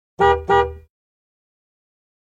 Sonneries » Sons - Effets Sonores » Klaxon camion mp3